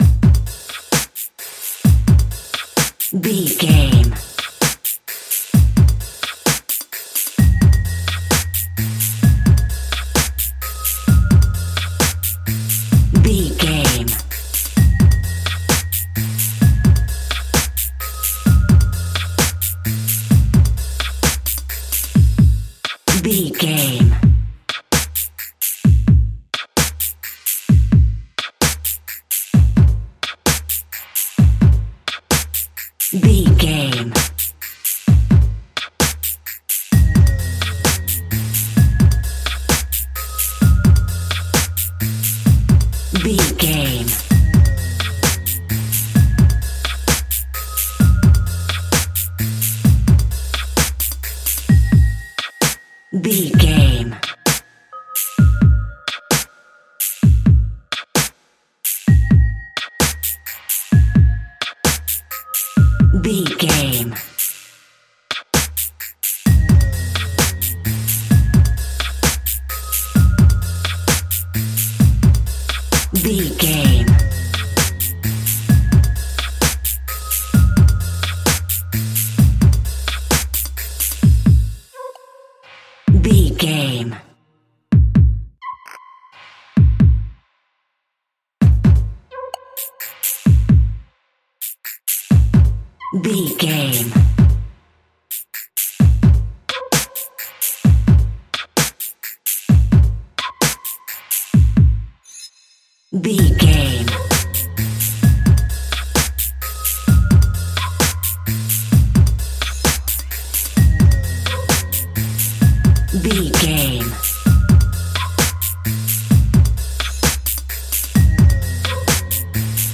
Rap and Dubstep Combo.
Aeolian/Minor
Slow
hip hop
chilled
laid back
hip hop drums
hip hop synths
piano
hip hop pads